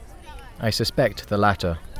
noisy-speech-files